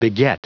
Prononciation du mot beget en anglais (fichier audio)